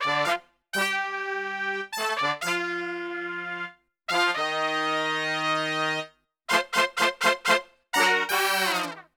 FUNK4 G M.wav